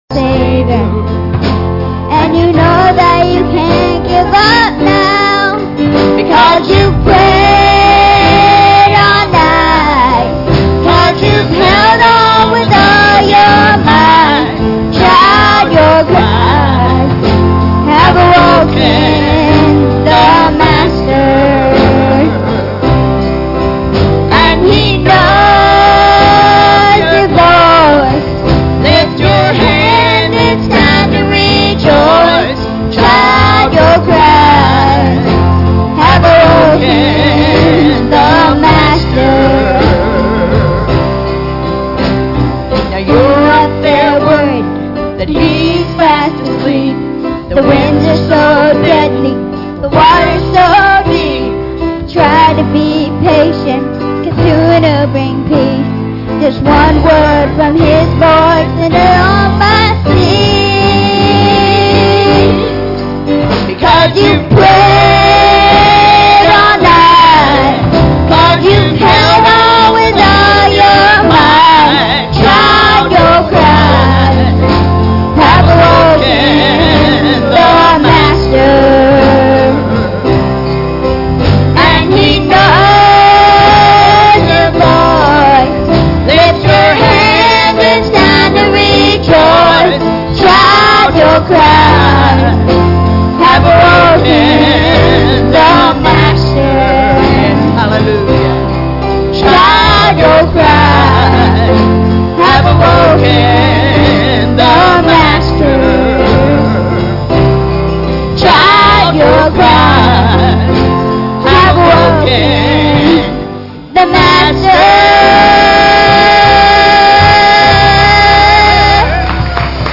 "John 12:1-2" Service Type: Sunday Evening Services Topics